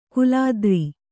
♪ kulādri